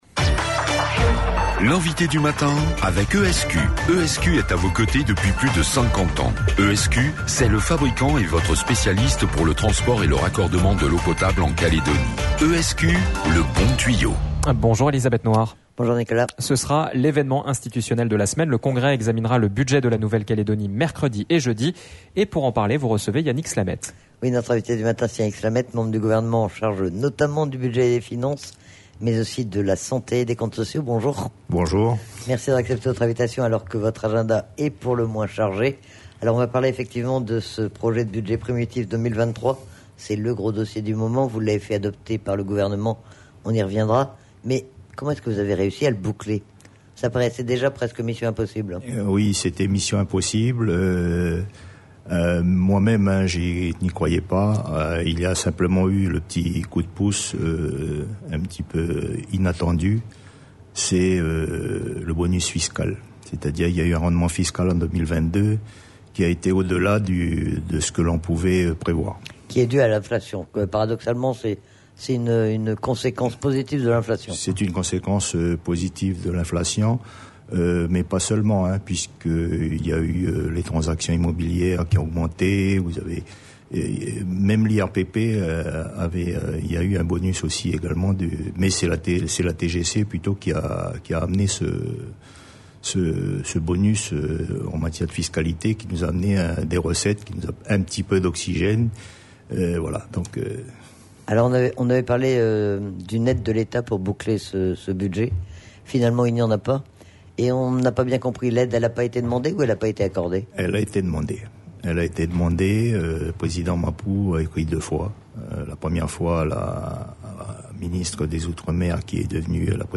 Yannick Slamet, membre du gouvernement en charge notamment du budget et des finances, était notre invité ce matin à 7h30. L'occasion de l'interroger sur le budget primitif 2023 de la Nouvelle Calédonie qui doit être examiné au congrès de la Nouvelle Calédonie mercredi et jeudi prochain.